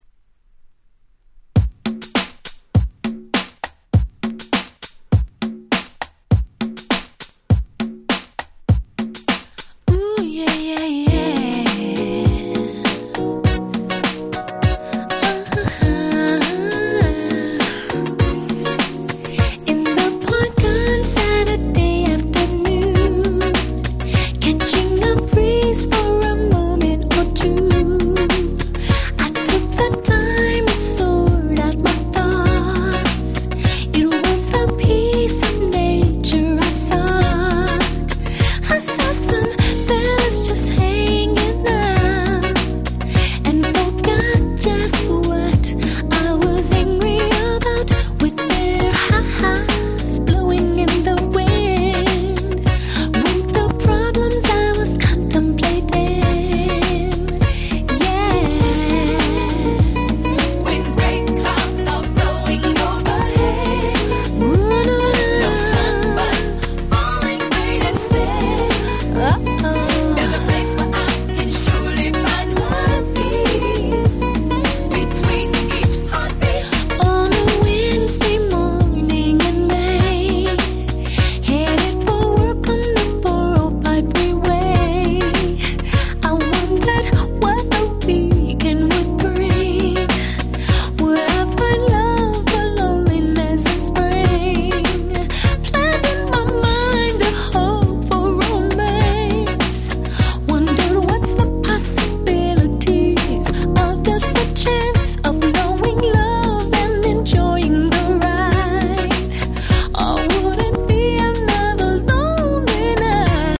03' Very Nice R&B !!